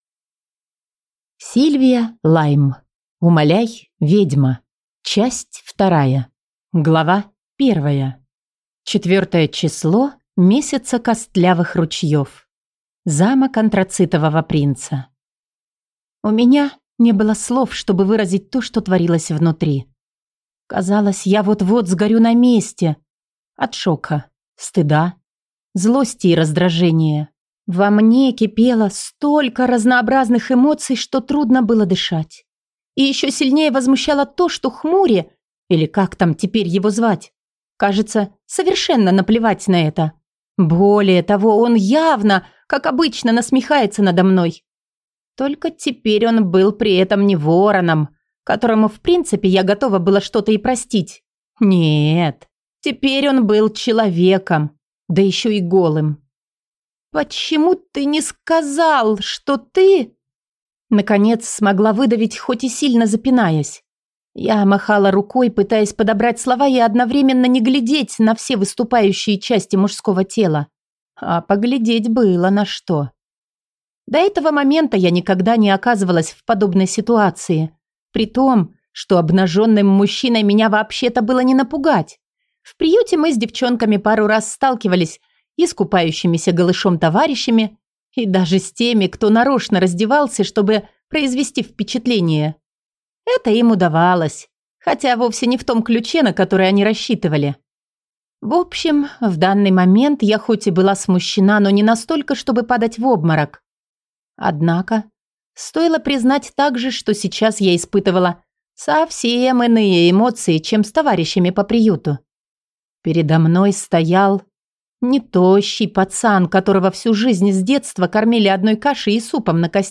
Аудиокнига Умоляй, ведьма. Часть 2 | Библиотека аудиокниг